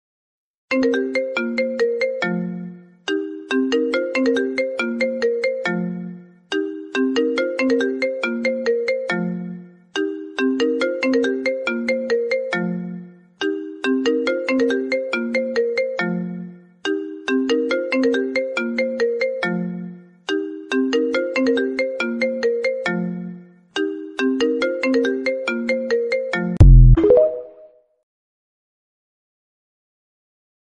Apple iPhone Ringtone sound